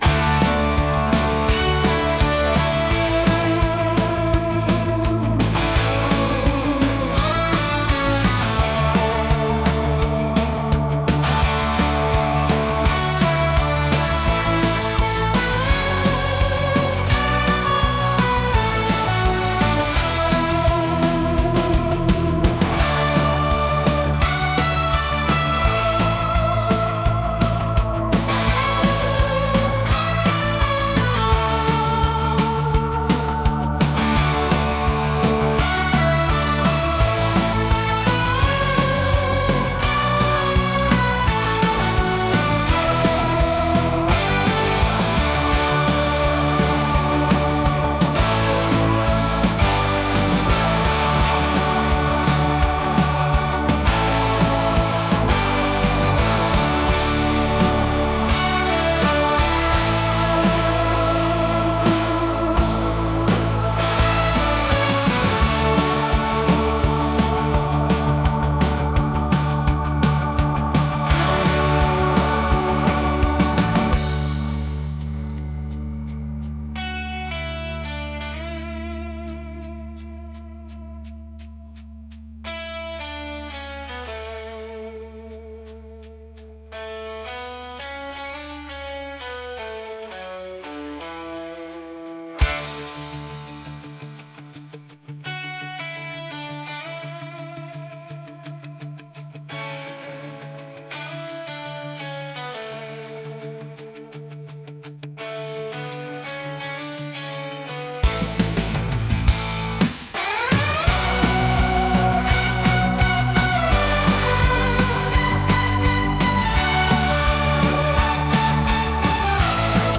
rock | retro | electronic